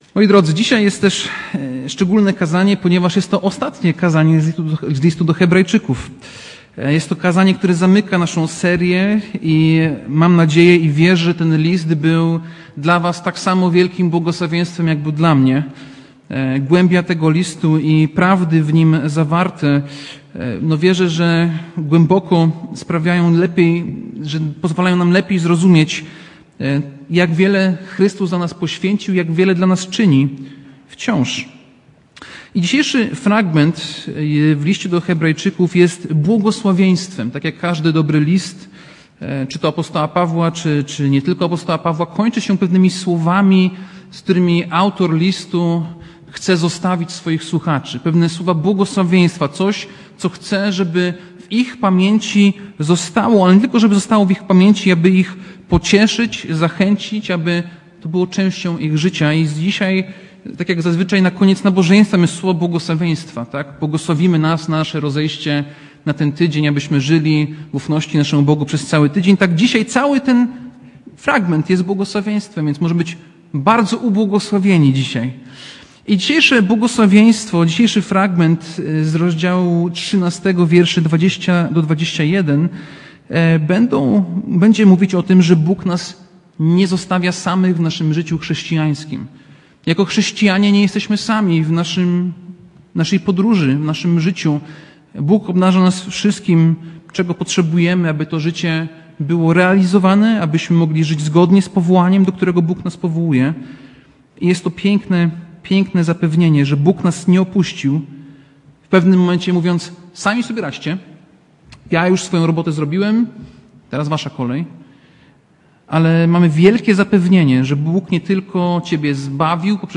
Passage: List do Hebrajczyków 13, 20-21 Rodzaj Usługi: Kazanie